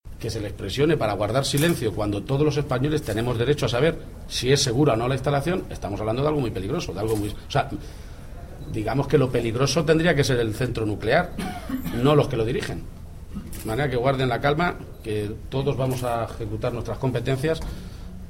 Presidente Jueves, 10 Septiembre 2015 - 1:45pm El presidente de Castilla-La Mancha, Emiliano García-Page, se ha referido hoy, durante la inauguración de la empresa Ingeteam en Albacete, a las presiones que están denunciando los trabajadores del Consejo de Seguridad Nuclear al hilo del cementerio nuclear en Villar de Cañas. corte_presidente_atc.mp3